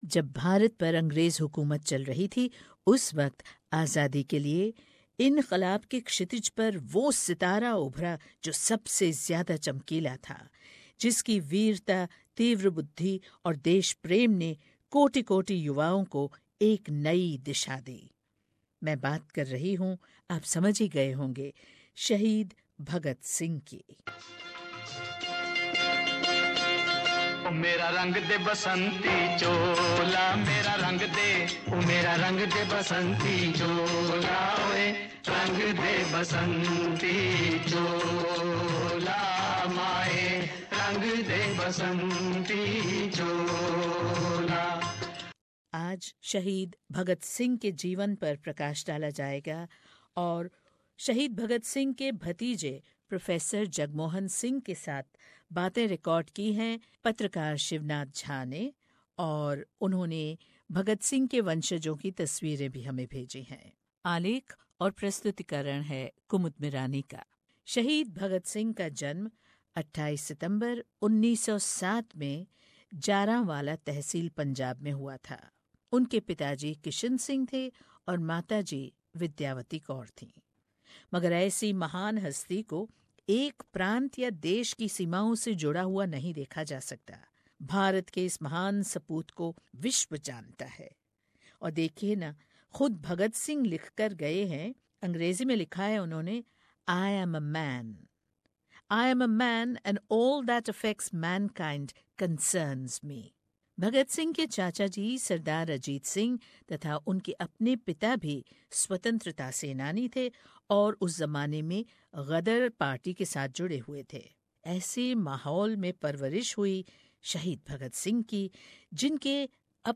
Do tune in for this exclusive report.